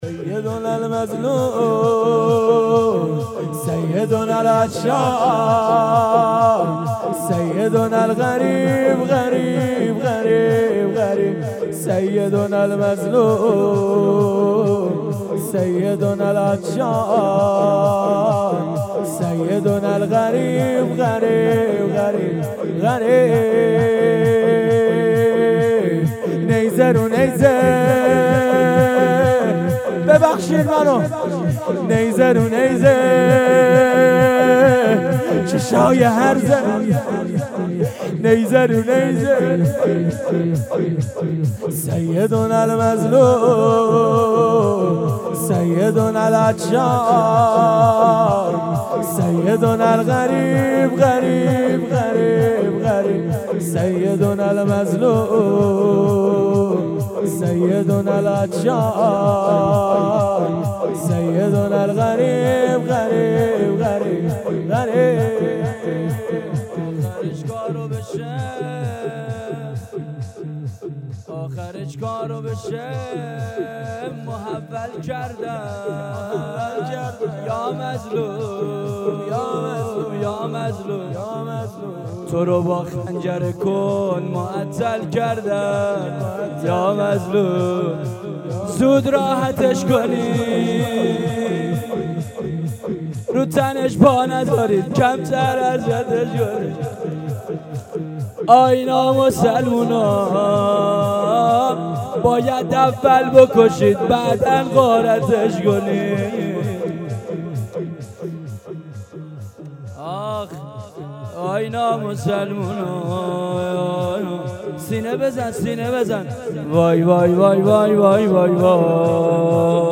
شور روضه | سیدنا المظلوم